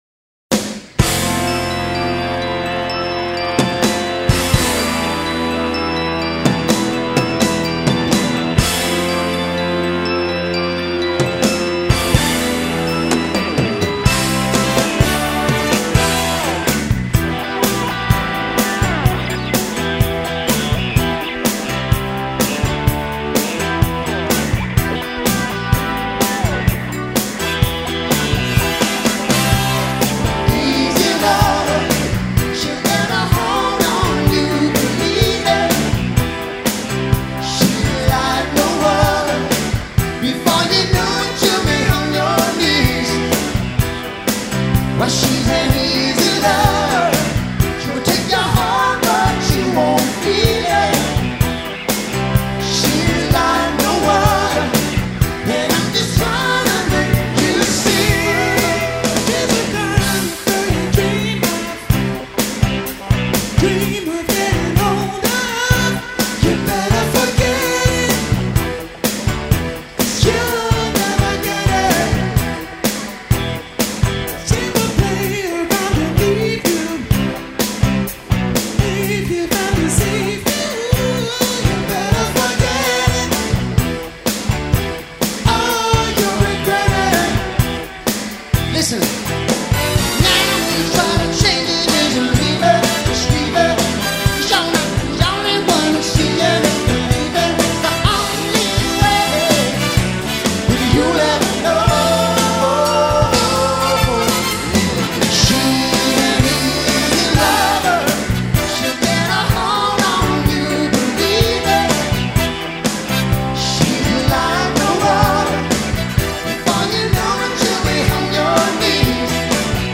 bass
falsetto harmonies